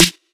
80SELE-SNR.wav